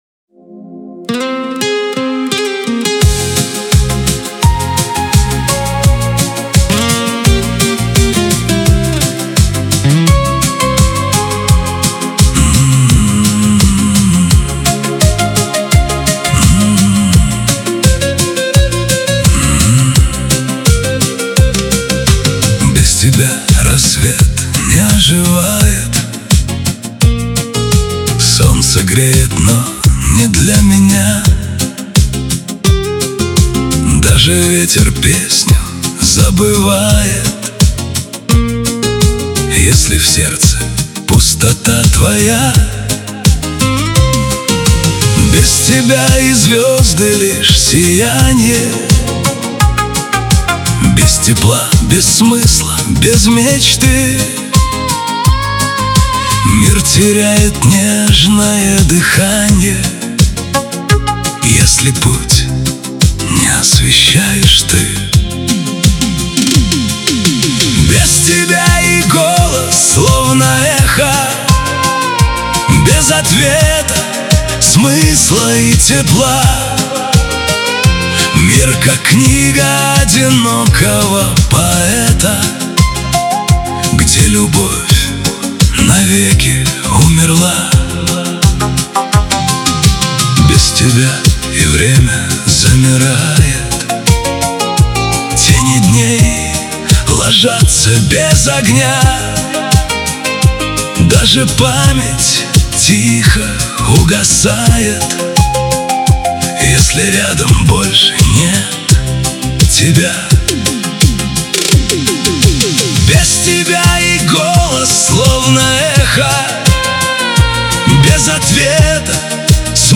Шансон 2026